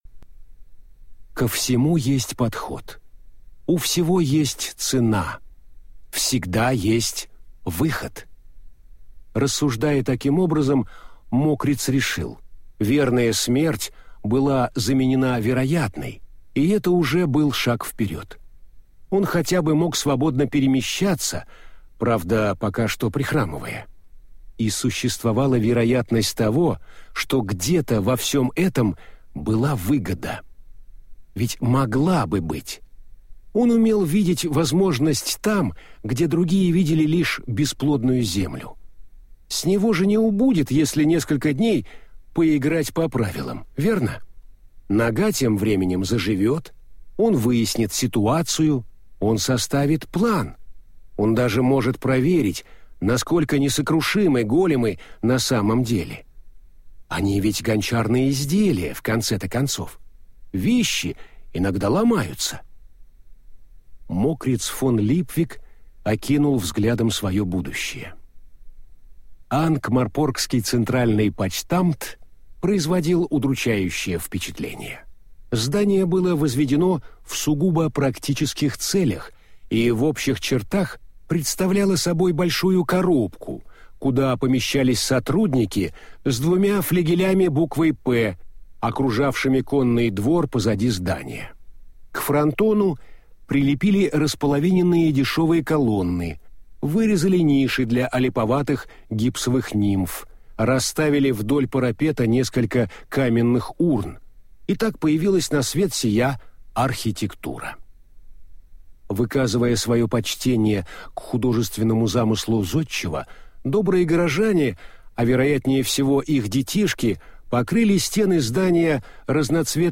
Аудиокнига Держи марку! Делай деньги!